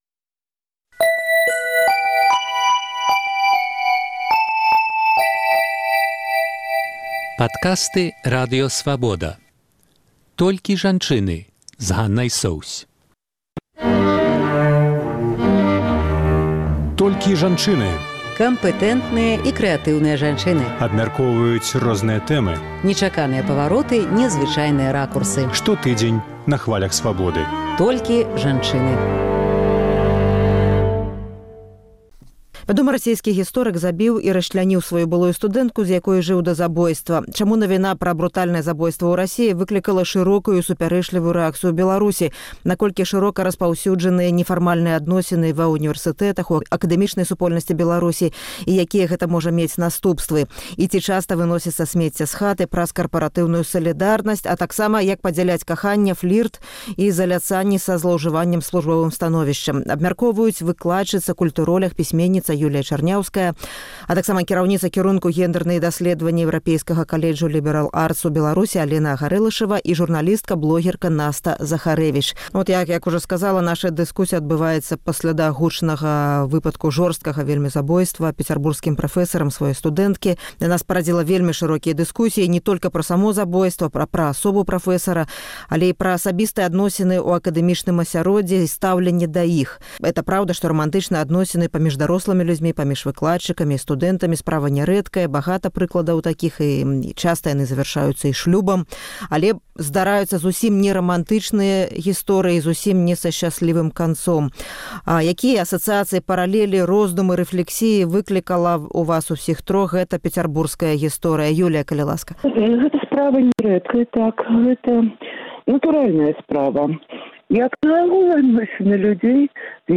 І як адрозьніць каханьне і заляцаньні ад злоўжываньня службовым становішчам? Абмяркоўваюць выкладчыца, культуроляг, пісьменьніца